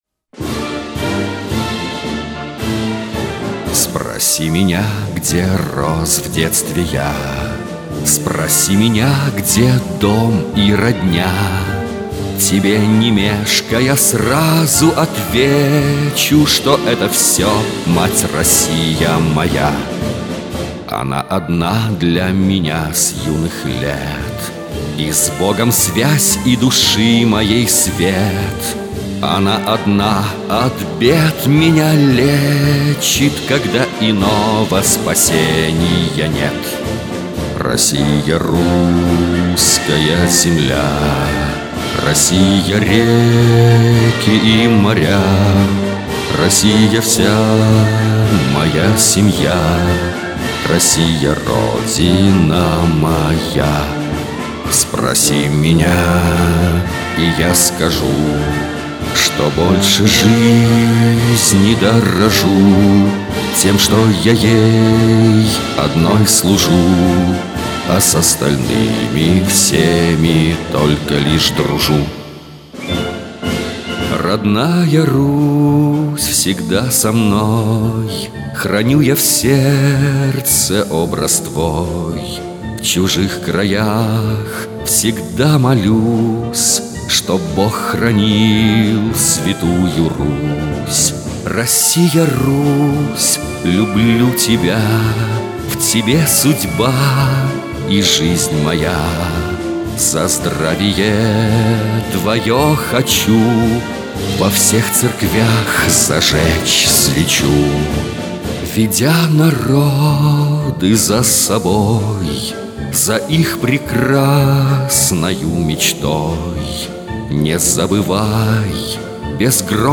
На мотив русского марша